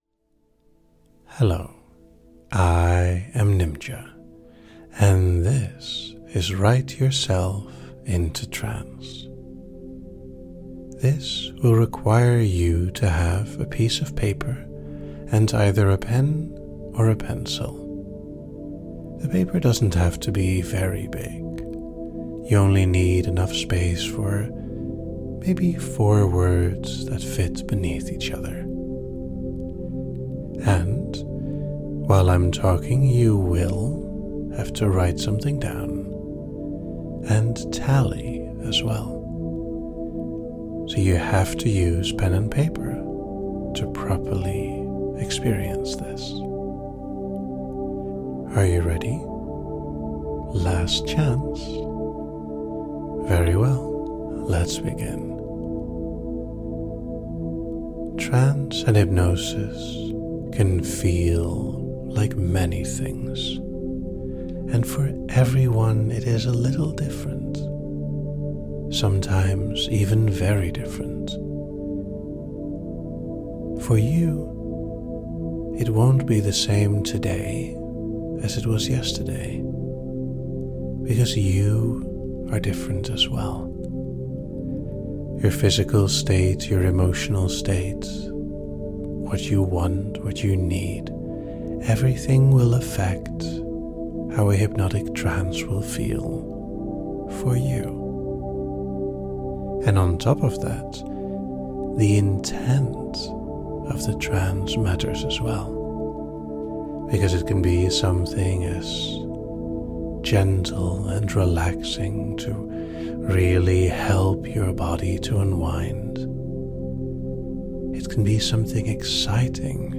Experience a lovely hypnotic trance as you write the words you feel.